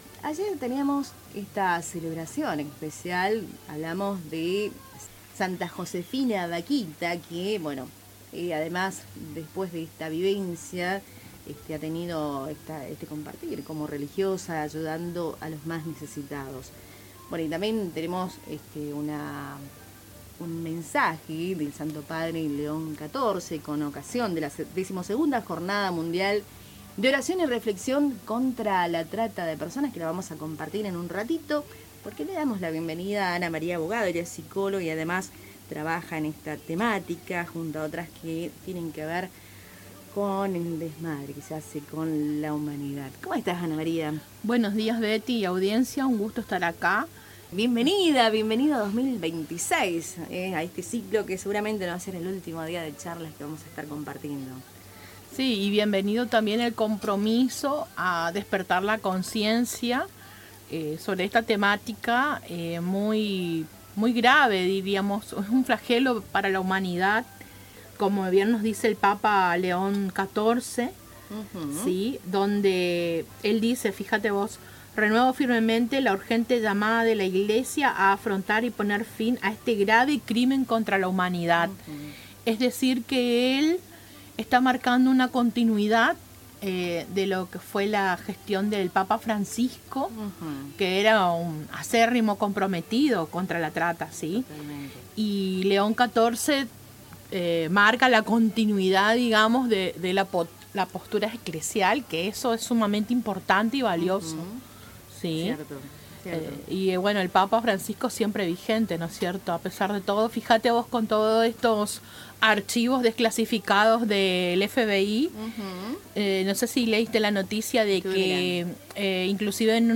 Durante la entrevista, la especialista detalló distintos casos de trata que evidencian la magnitud del problema.